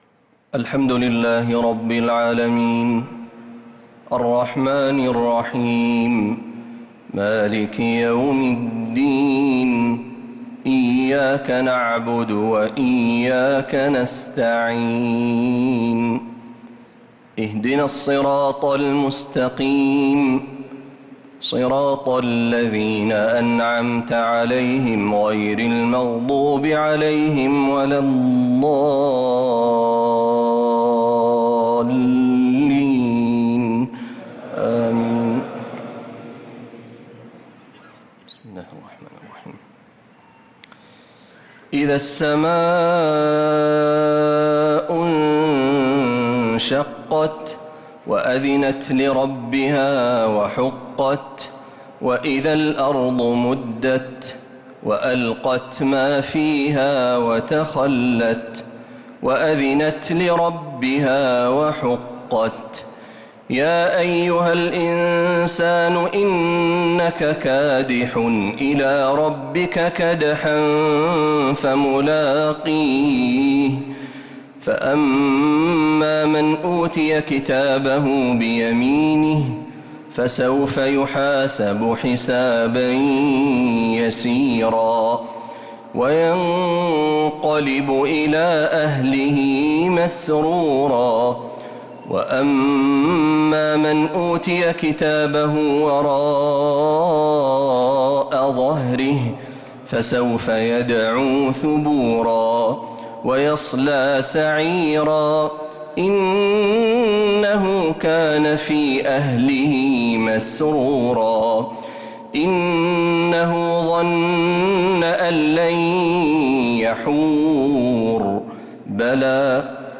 تراويح ليلة 29 رمضان 1446هـ من سورة الانشقاق إلى سورة التكاثر | taraweeh 29th night Ramadan 1446H Surah Al-Inshiqaq to Surah At-Takathur > تراويح الحرم النبوي عام 1446 🕌 > التراويح - تلاوات الحرمين